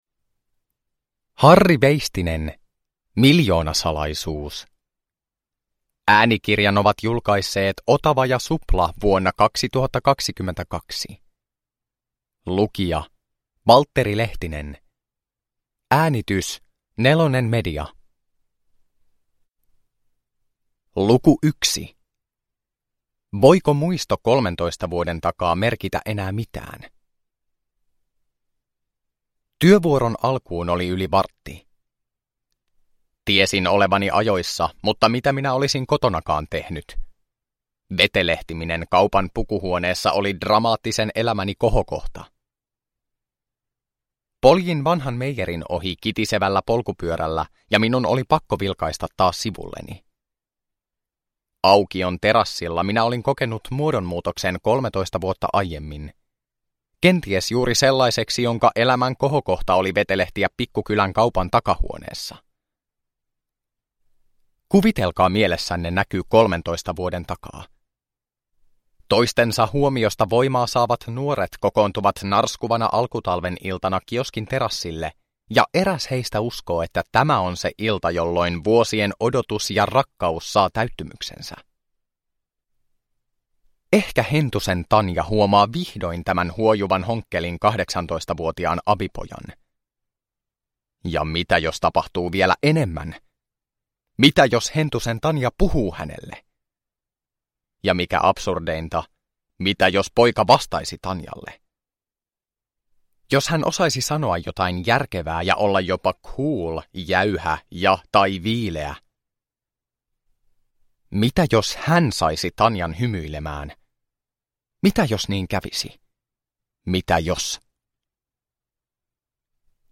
Miljoonasalaisuus – Ljudbok – Laddas ner